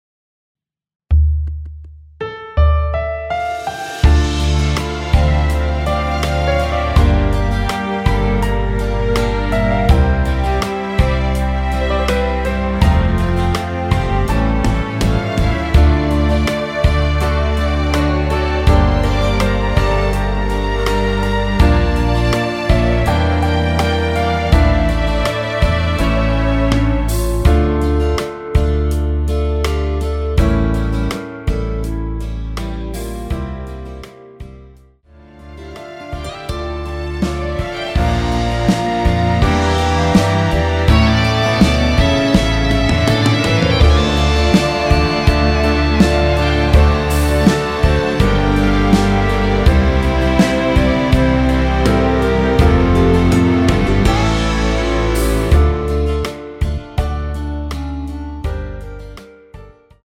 원키 멜로디 포함된 MR입니다.
Dm
앞부분30초, 뒷부분30초씩 편집해서 올려 드리고 있습니다.